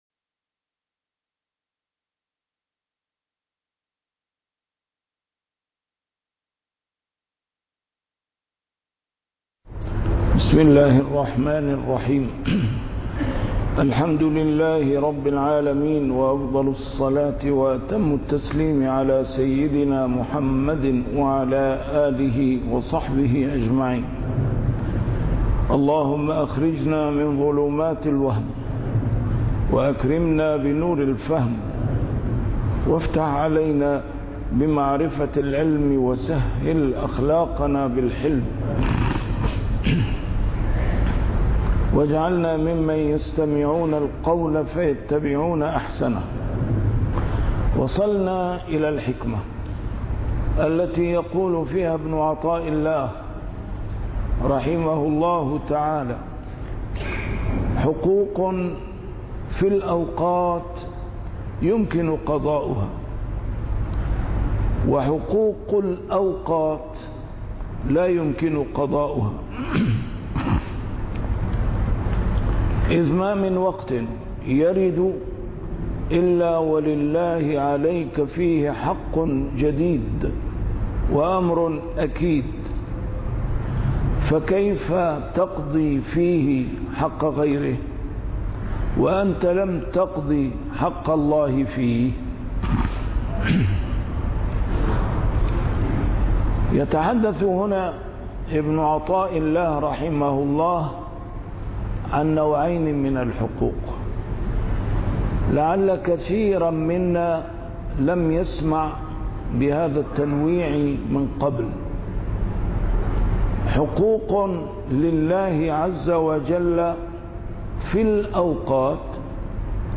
A MARTYR SCHOLAR: IMAM MUHAMMAD SAEED RAMADAN AL-BOUTI - الدروس العلمية - شرح الحكم العطائية - الدرس رقم 228 شرح الحكمة رقم 207